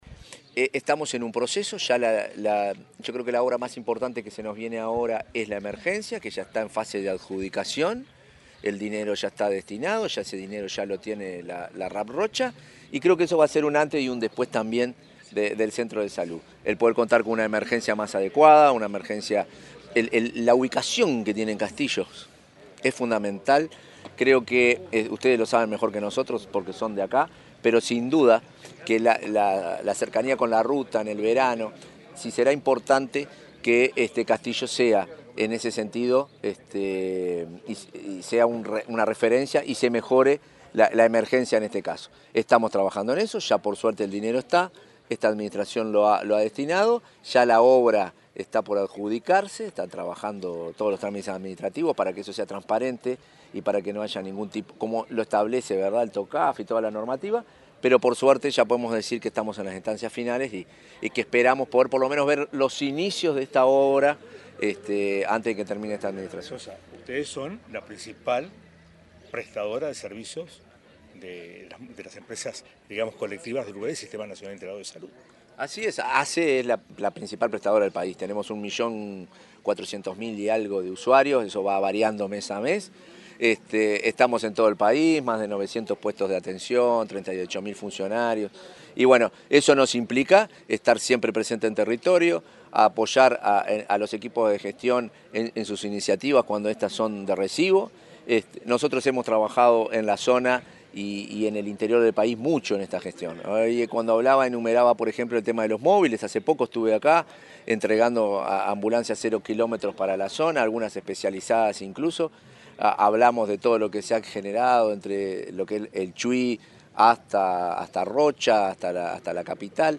Declaraciones del presidente de ASSE, Marcelo Sosa
Declaraciones del presidente de ASSE, Marcelo Sosa 20/11/2024 Compartir Facebook X Copiar enlace WhatsApp LinkedIn El martes 19, el presidente de la Administración de los Servicios de Salud del Estado (ASSE), Marcelo Sosa, dialogó con la prensa durante la inauguración de obras de remodelación en el centro auxiliar de Castillos, en el departamento de Rocha.